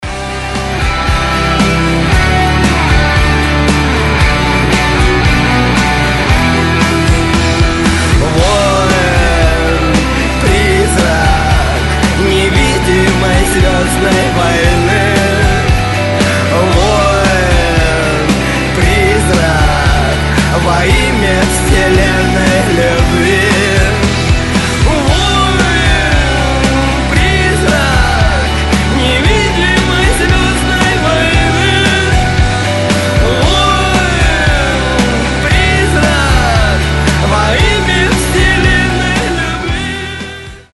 громкие
вдохновляющие
Soft rock
легкий рок
русский рок
воодушевляющие